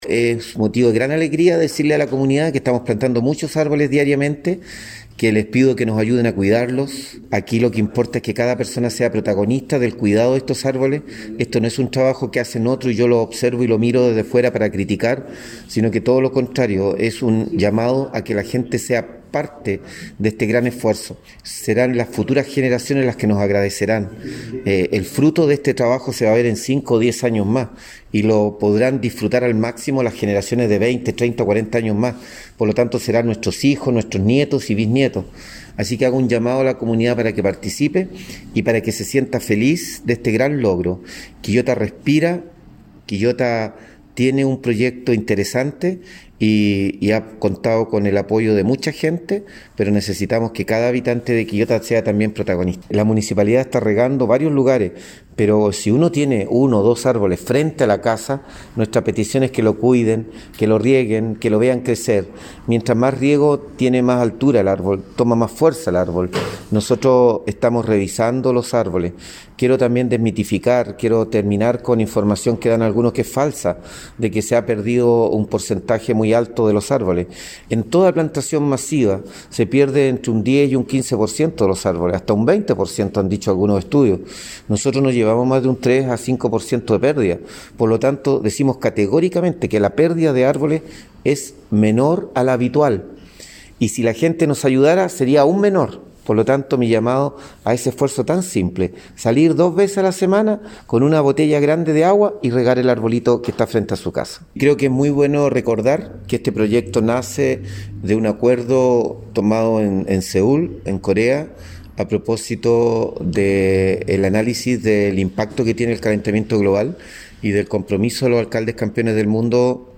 Alcalde-Luis-Mella-Quillota-Respira.mp3